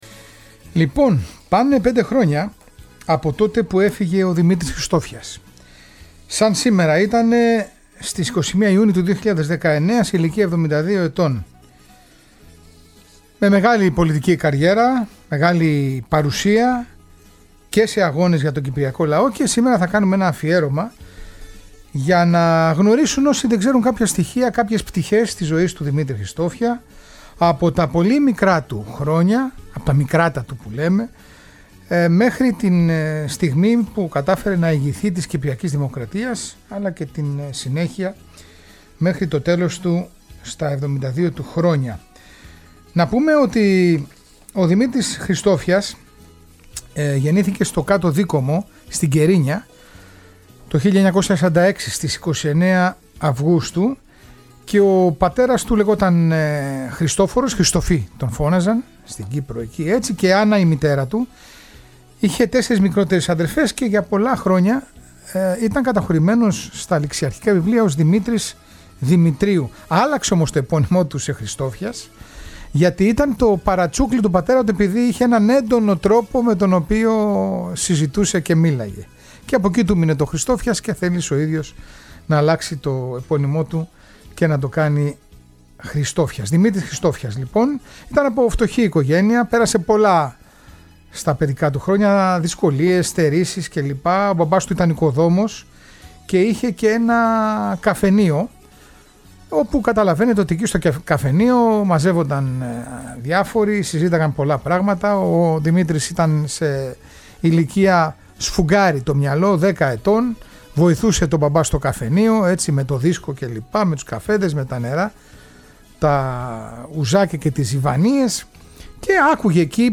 Ένα ολοκληρωμένο αφιέρωμα με ιστορικά στοιχεία, συνεντεύξεις αλλά και αποσπάσματα από ομιλίες και τοποθετήσεις του πρώην Γραμματέα του ΑΚΕΛ.